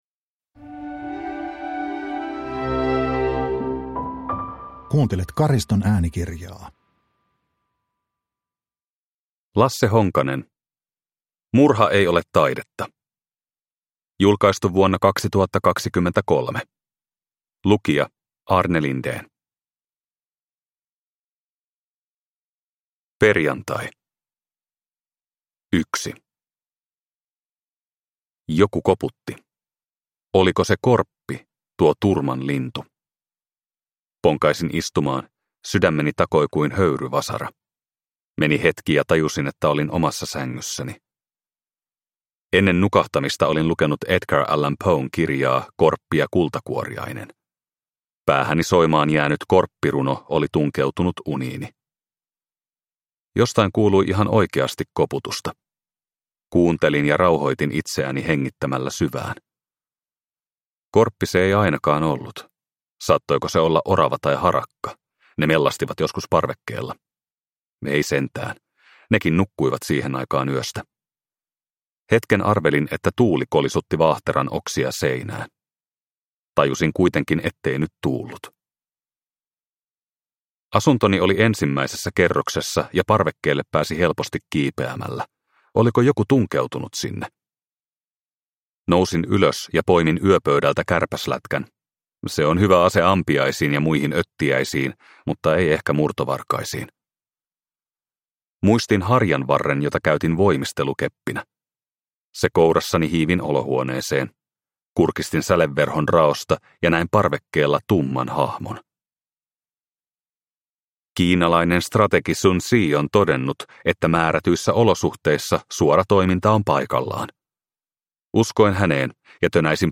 Murha ei ole taidetta (ljudbok) av Lasse Honkanen